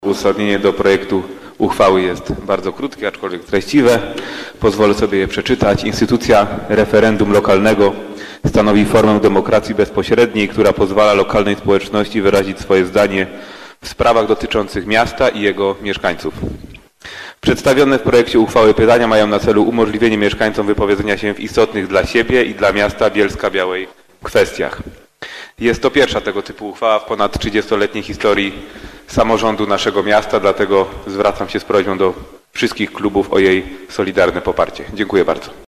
Uzasadnienie do projektu odczytał szef prezydenckiego klubu – Maksymilian Pryga.